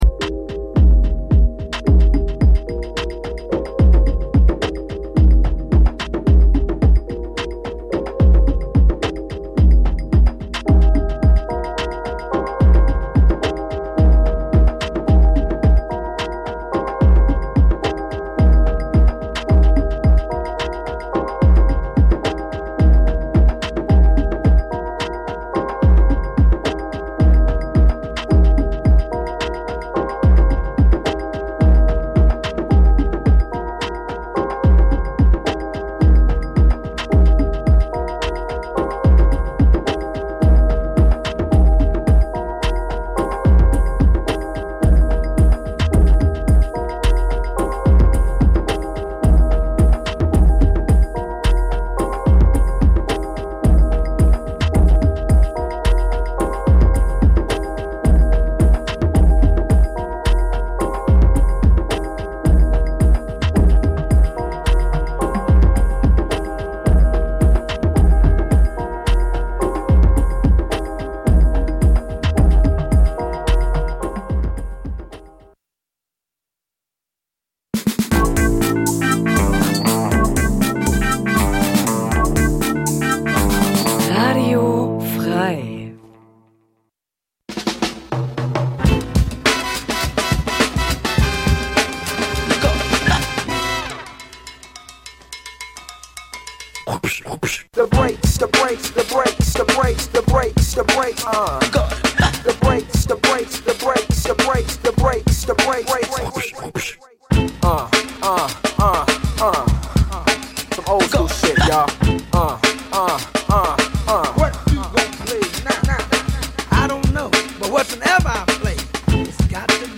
Hinter WHAT ABOUT BREAKS? verbergen sich unter dem alles �berspannenden Dach der Hip Hop Kultur neben den vorher genannten Stilrichtungen auch BLUES, LATIN, ELECTRO, REGGAE und POP mit jeder Menge Hintergrundinfos zur Musik und den K�nstlern.